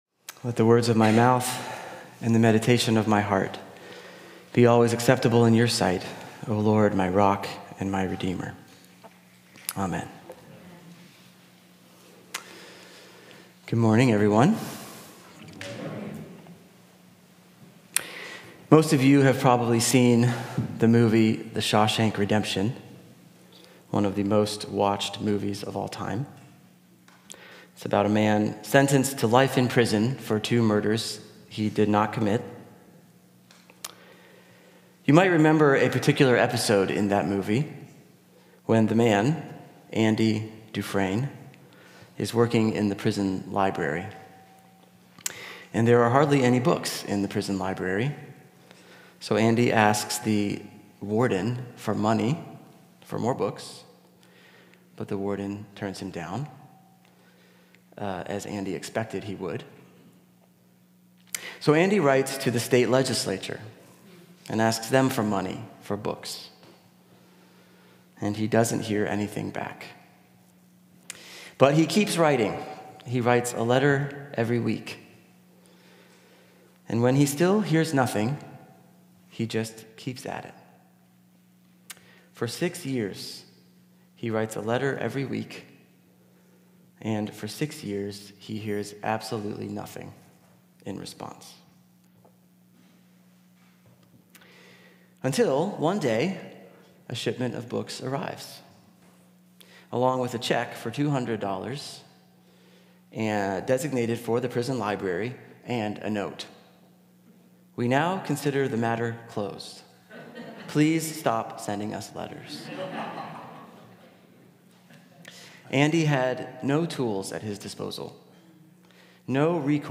Sermon -